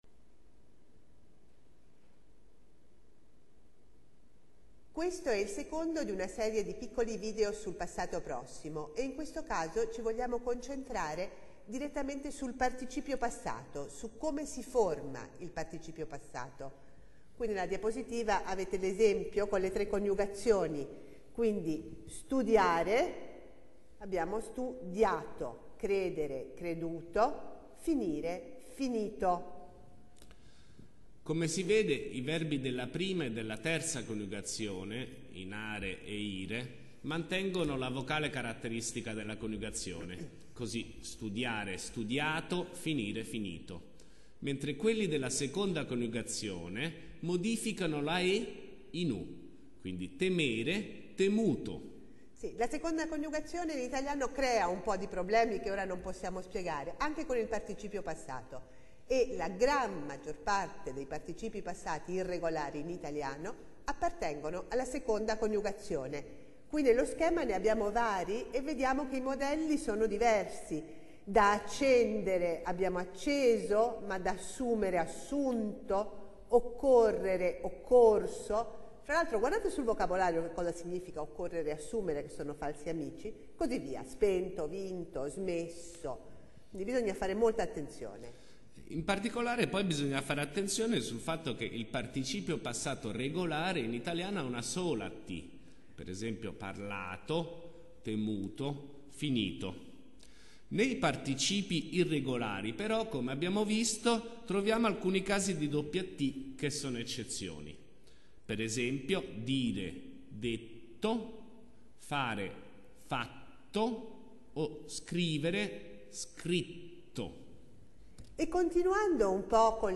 Video Clase